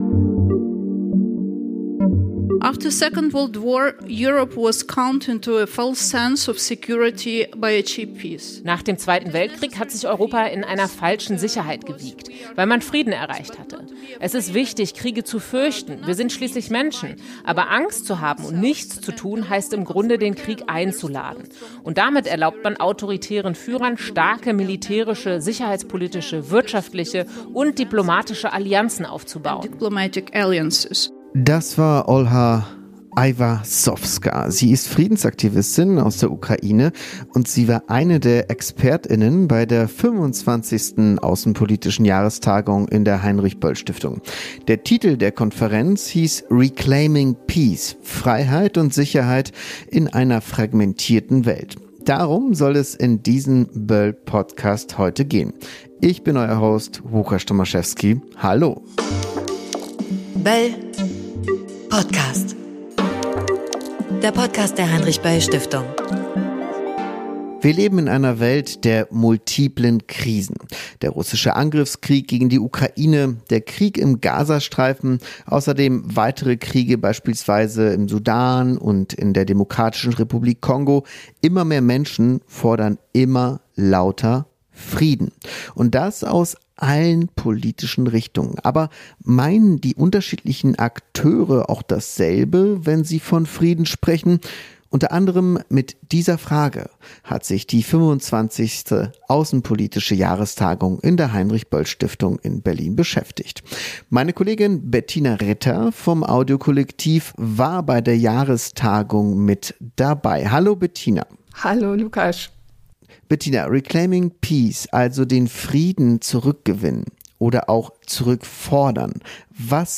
Antworten von Experten u.a. aus der Ukraine, Sudan und Japan gab es bei der 25. Außenpolitischen Jahrestagung.